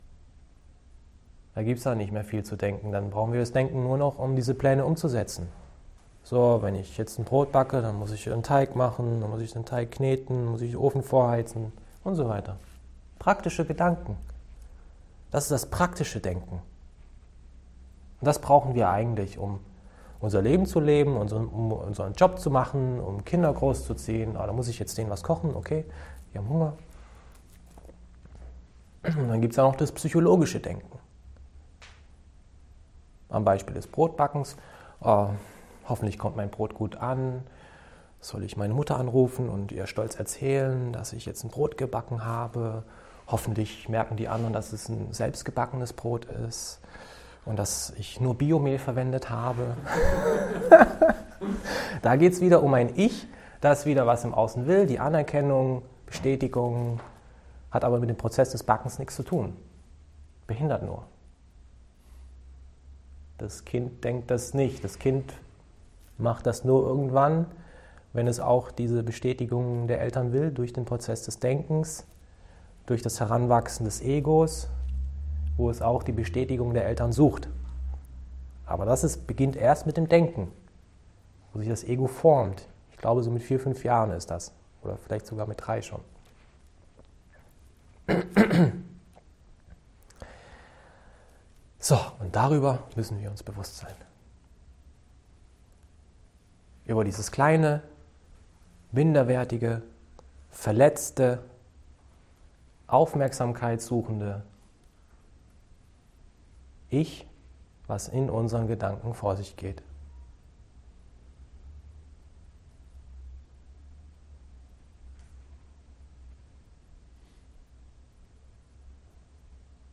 Mitschnitt vom Workshop Mut und Vertrauen 2016
Die Fragen der Teilnehmer werden darin auch beantwortet.